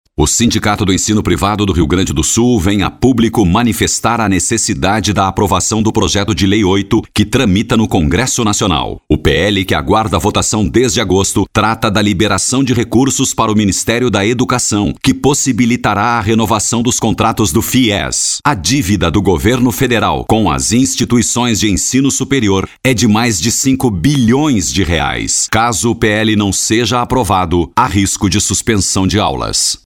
Locutor voz Grave, a disposição para gravação de Spots de Rádio e TV, bem como Esperas Telefônicas e documentários
• spot